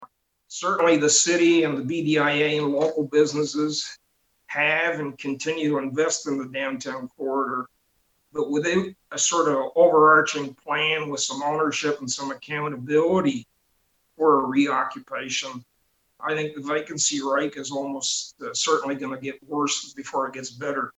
Belleville city council virtual meeting, June 8, 2020 (Belleville YouTube)
Councillor Bill Sandison noted it would be a boost to the downtown and the issue should be a priority for the Economic Development Committee.